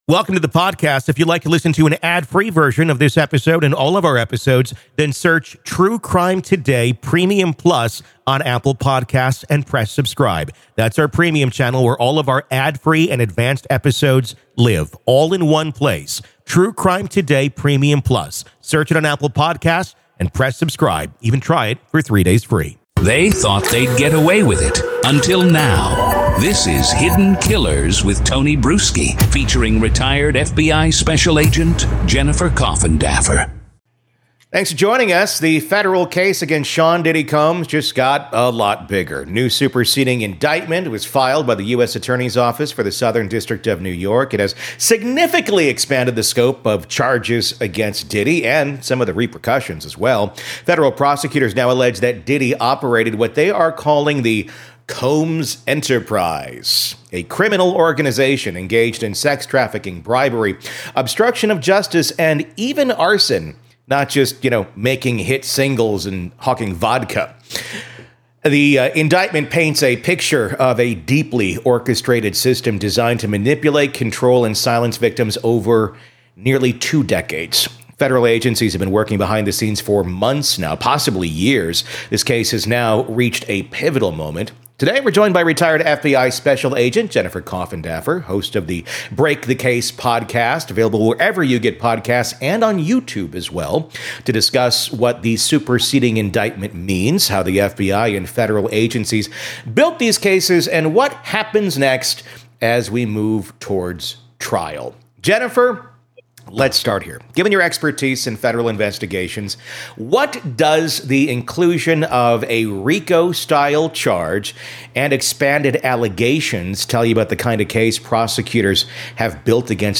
True Crime Today | Daily True Crime News & Interviews